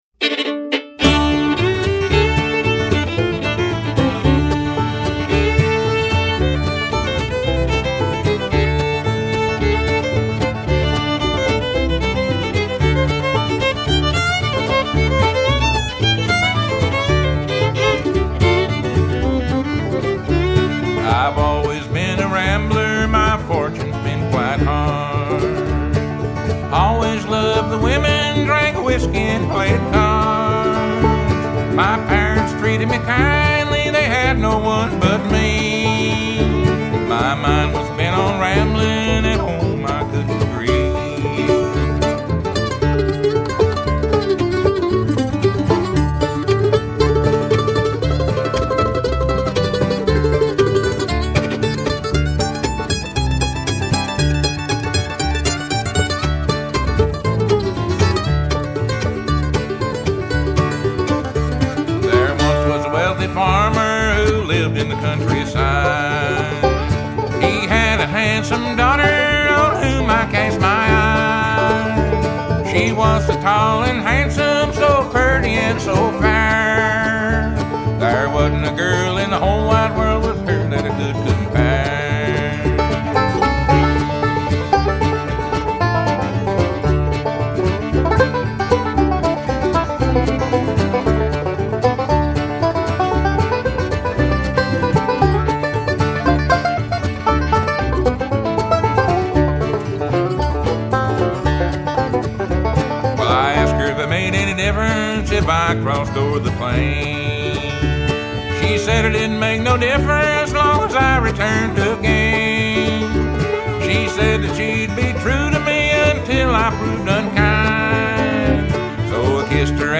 fiddle
banjo
mandolin & vocals
bass
lead vocals, mandolin
vocals